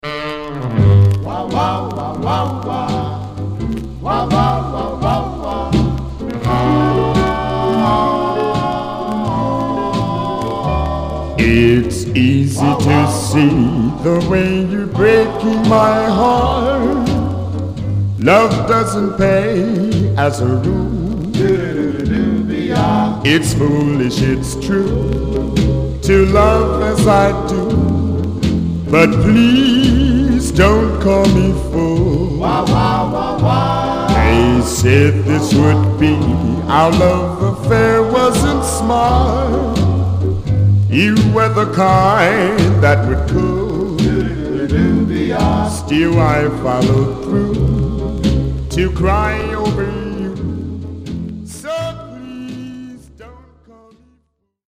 Nice Early Pop, Full Group Condition: M-
Stereo/mono Mono
Male Black Groups